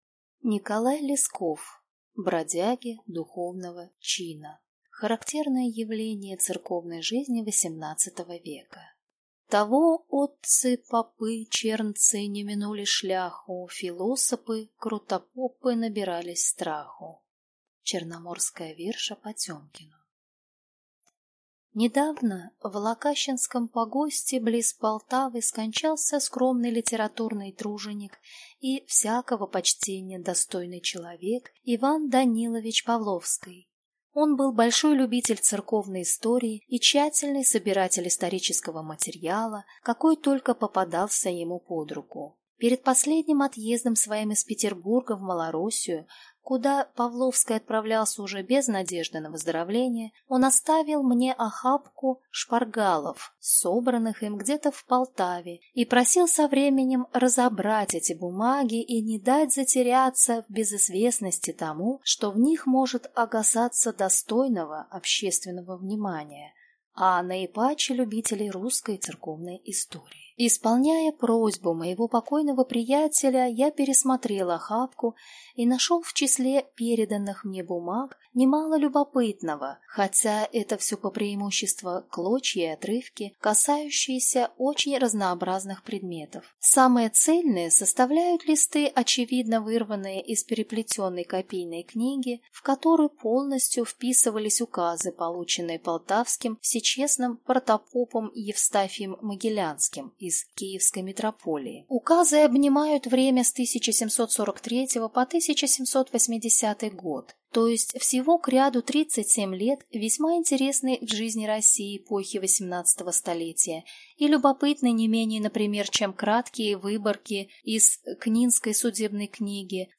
Аудиокнига Бродяги духовного чина | Библиотека аудиокниг